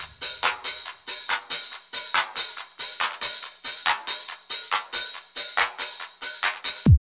15.1 LOOP1.wav